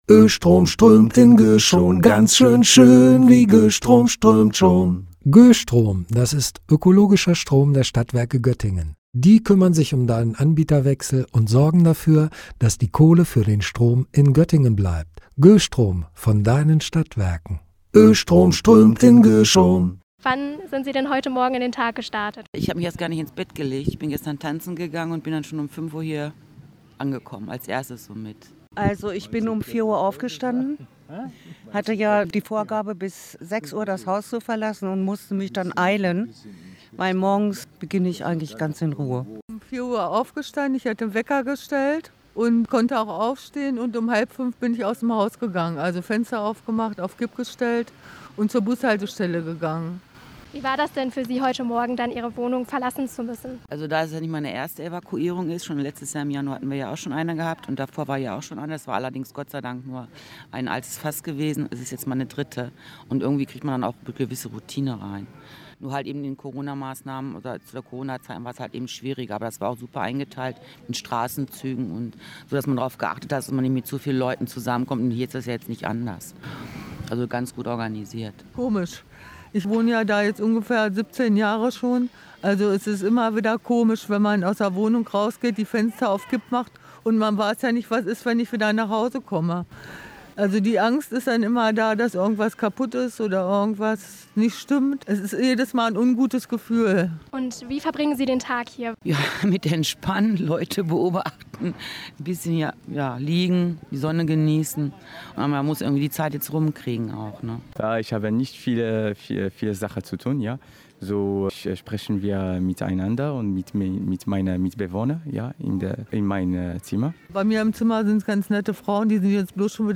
Umfrage unter den Evakuierten